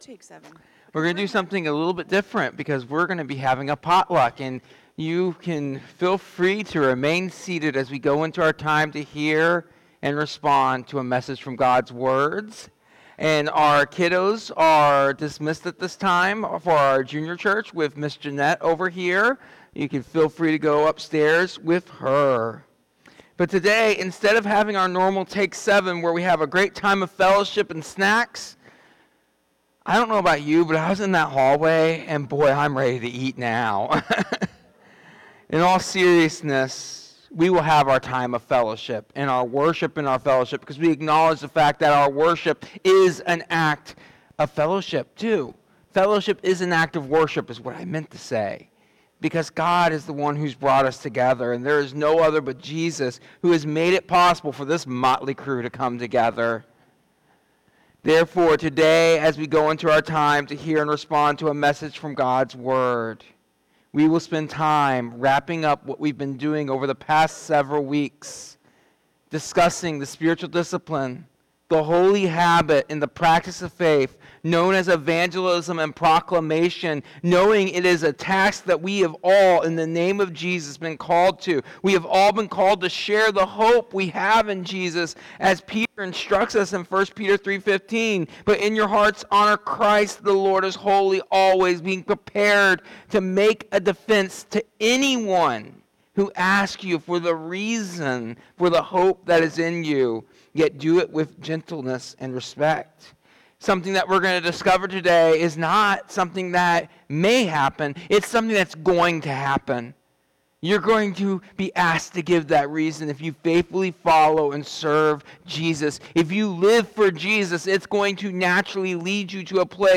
This sermon emphasizes that all Christians are called to share the hope they have in Jesus through both actions and words. Reflecting on the B.L.E.S.S. model—Begin with prayer, Listen, Eat, Serve, and Share—the message encourages believers to follow Jesus’ example in everyday life.